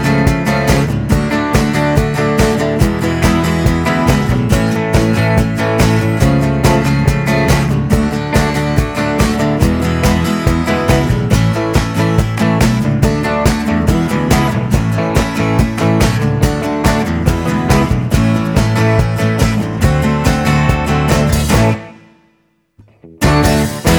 No Backing Vocals Rock 'n' Roll 2:58 Buy £1.50